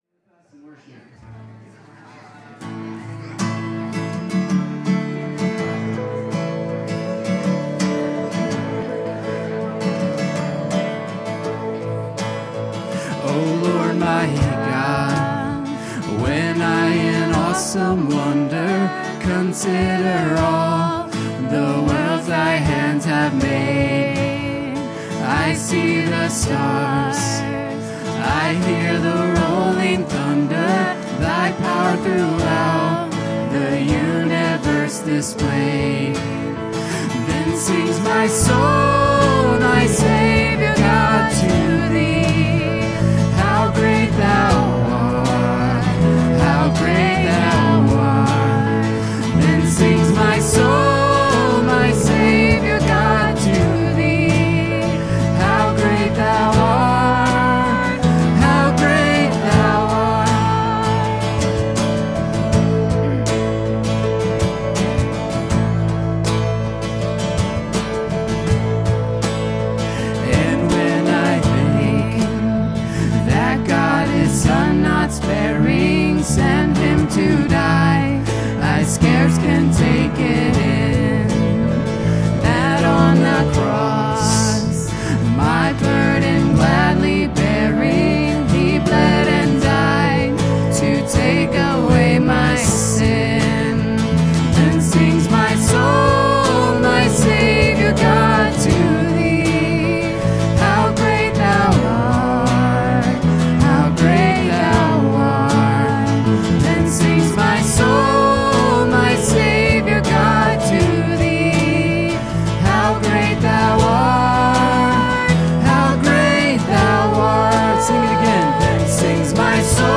Worship 2024-11-17